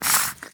Minecraft Version Minecraft Version snapshot Latest Release | Latest Snapshot snapshot / assets / minecraft / sounds / mob / fox / aggro5.ogg Compare With Compare With Latest Release | Latest Snapshot